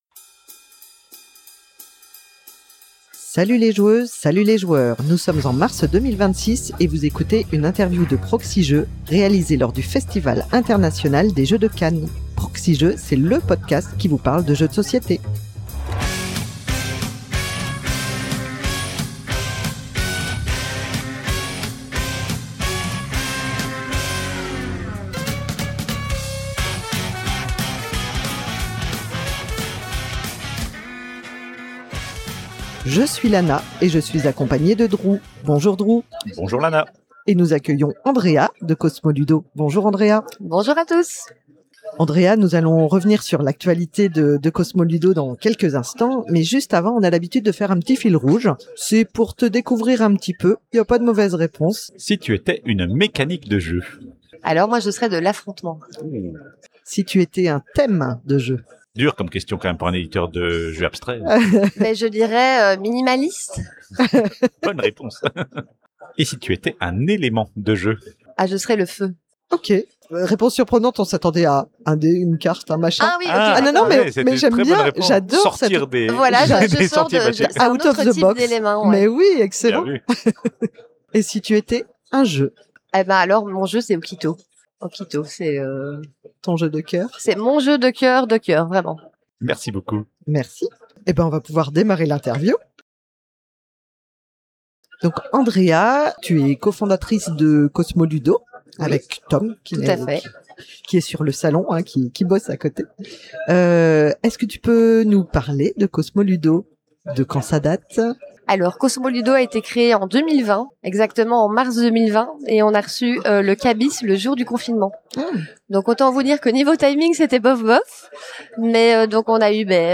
Interview
réalisée lors du Festival International des Jeux de Cannes 2026
Lors de son séjour au Festival International des Jeux de Cannes, la Proxi-Team a rencontré de nombreuses actrices et de nombreux acteurs du monde du jeu de société.